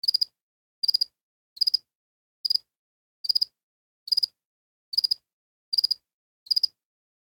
insectday_8.ogg